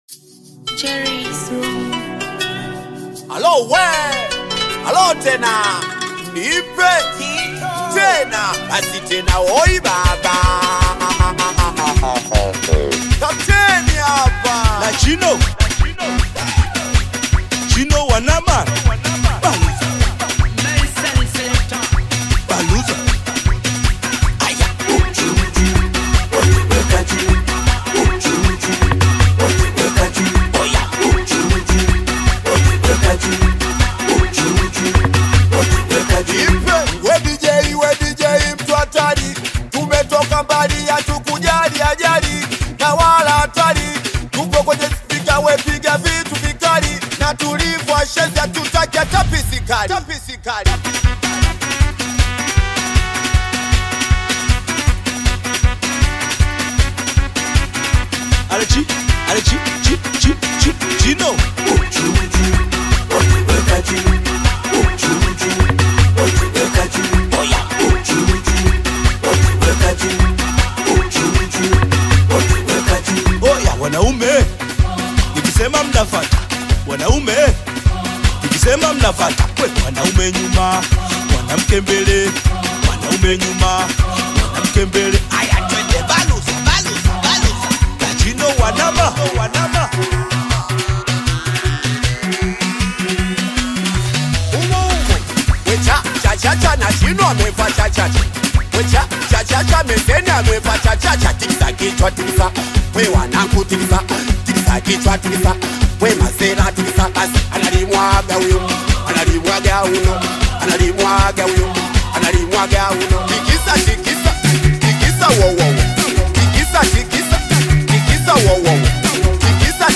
high-energy Singeli/Bongo Flava single
Genre: Singeli